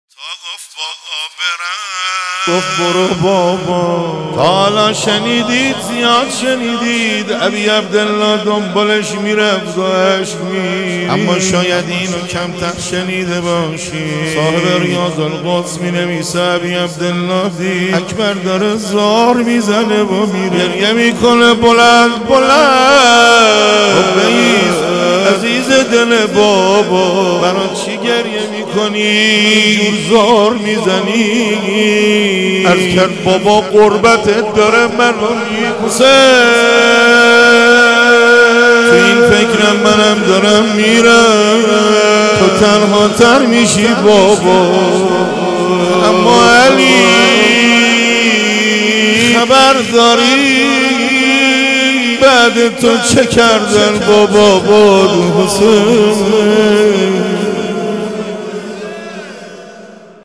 روضه بابا برم؟ | مداحی حاج حسن خلج | تهیه شده توسط خانه هنر پلان 3